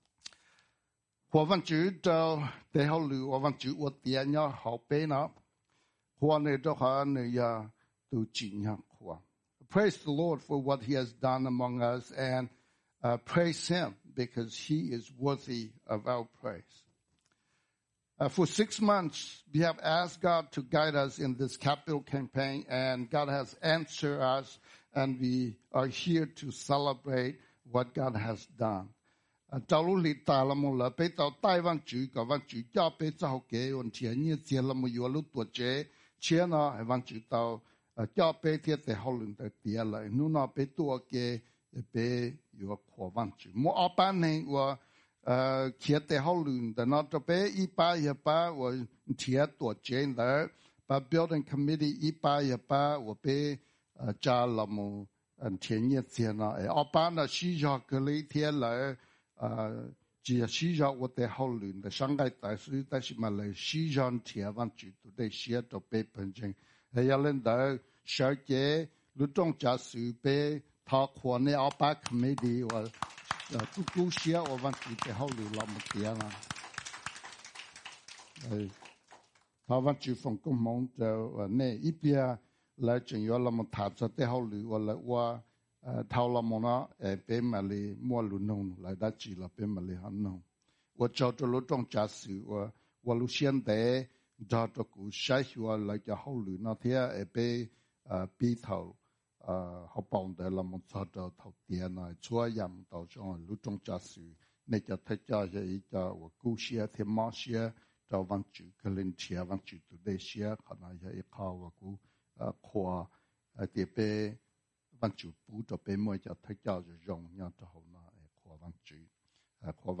Sermons Archive - Page 3 of 14 - Eternal Life Church
2019-06-30 – Combine Service